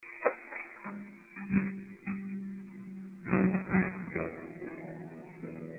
Here's A Few EVP's Featuring Sing Song Voices & Music